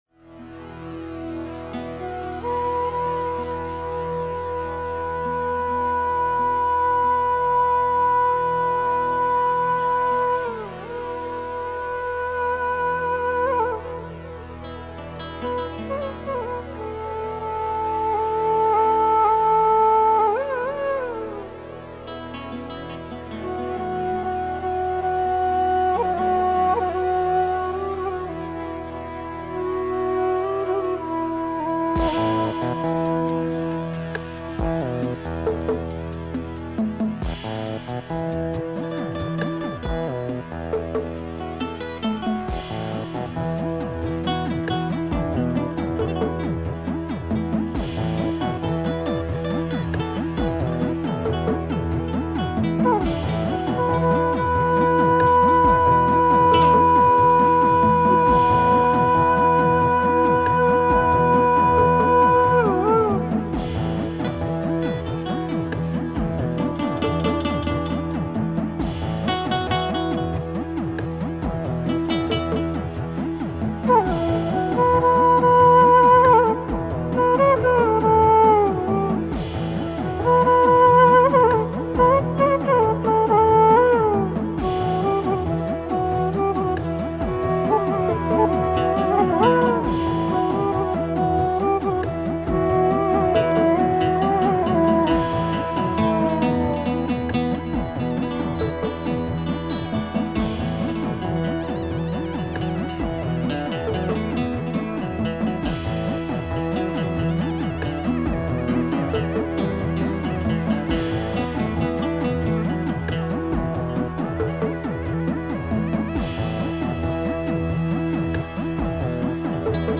flute
keyboards
Totally smoky, amazing shape !